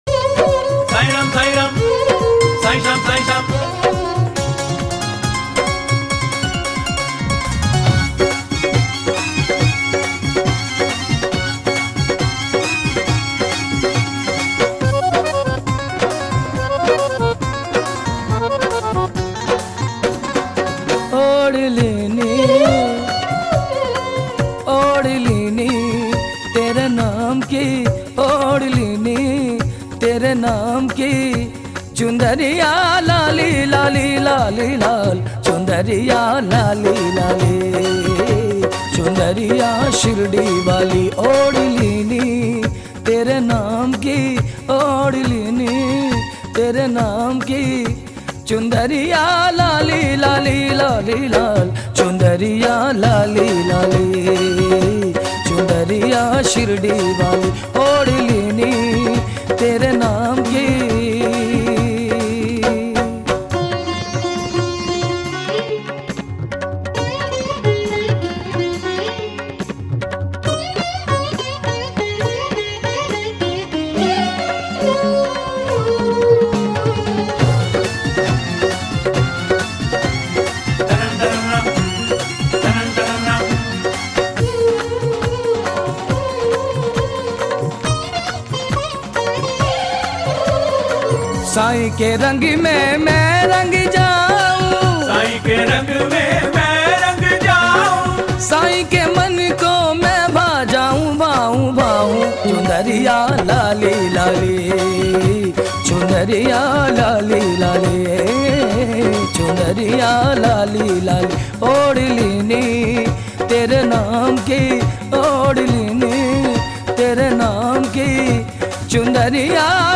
Devotional Song
Sai Baba Bhajan